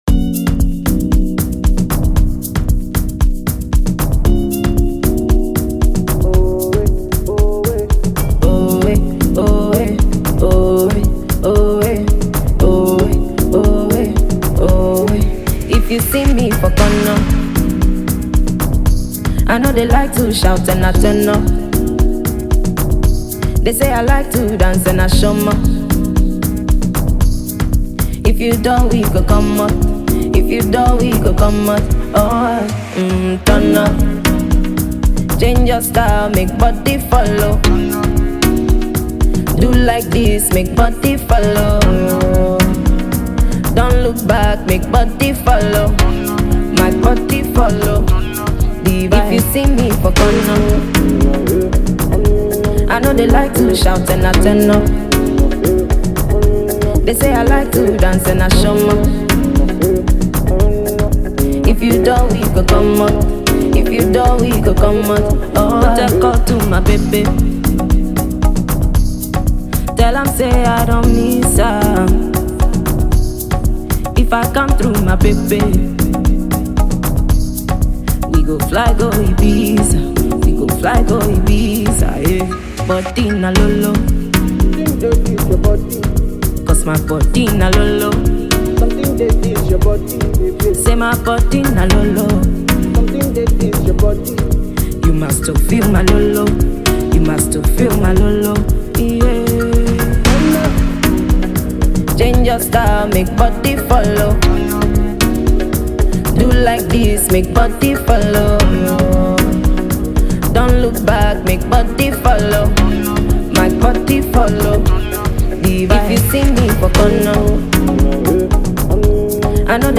Ghana MusicMusic
Dancehall